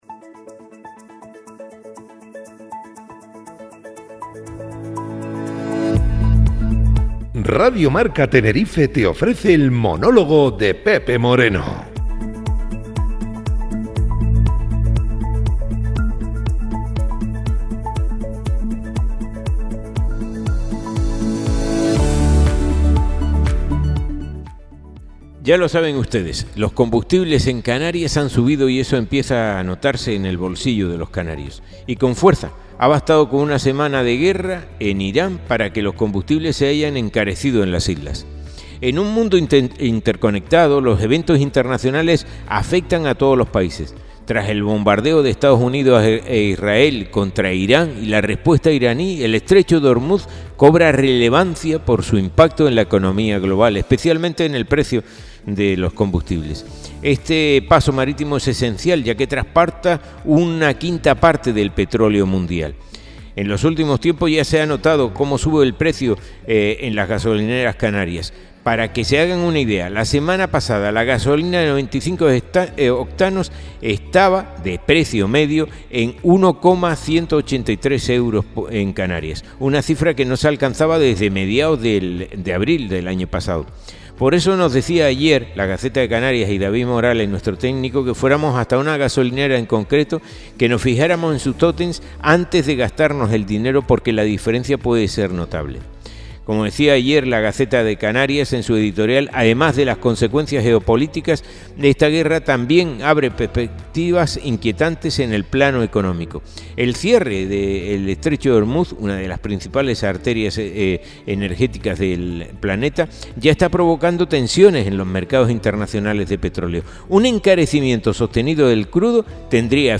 El Monólogo